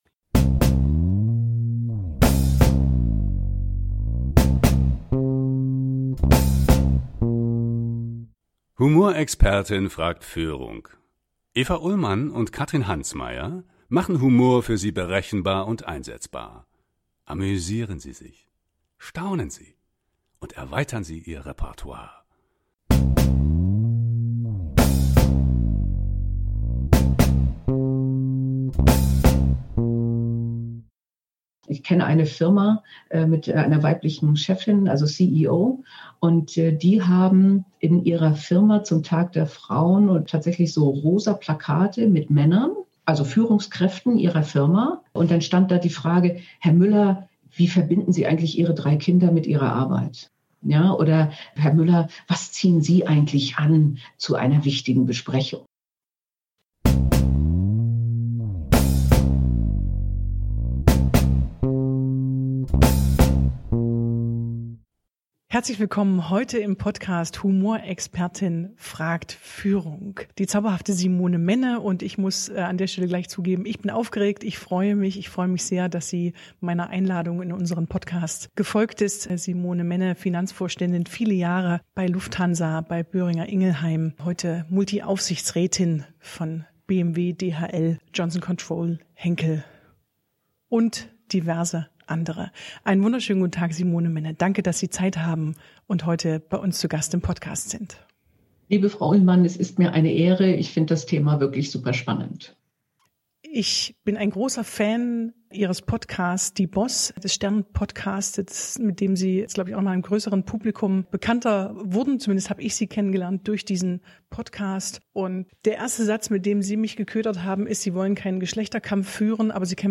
In diesem Podcastgespräch geht es um die Frage, ob Frauen genauso witzig sind wie Männer. Wir besprechen die humorvollen Möglichkeiten für gendergerechter Sprache und kommen auch an der Frauenquote nicht vorbei.